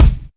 Track 16 - Kick OS.wav